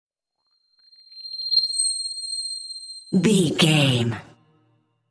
Sound Effects
Atonal
magical
mystical
special sound effects